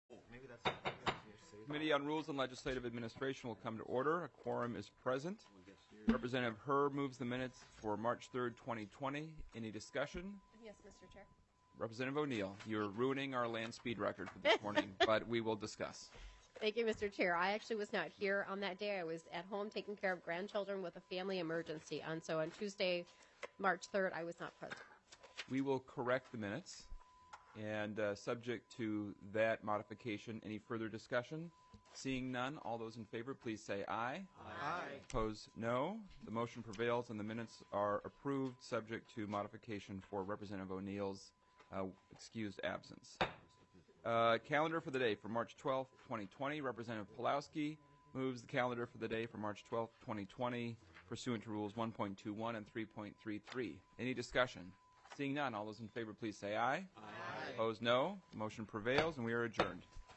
Chair: Rep. Ryan Winkler
Meeting:
200 State Office Building